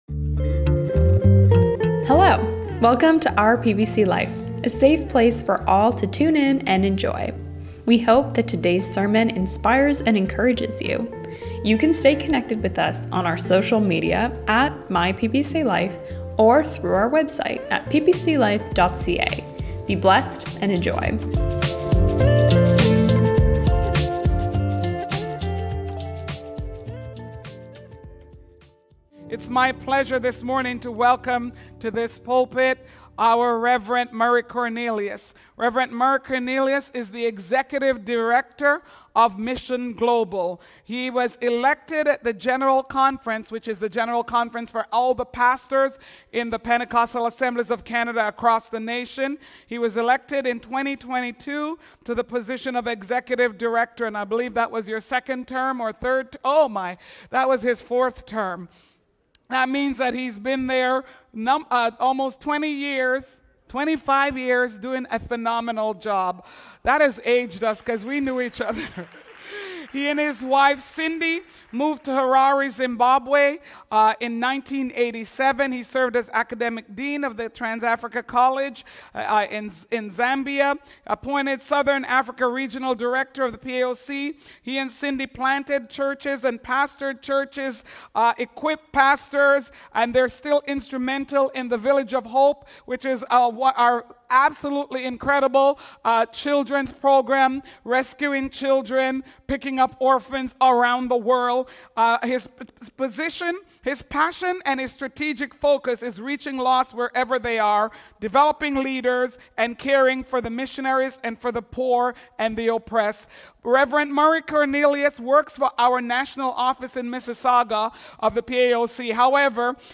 Don't miss this powerful sermon.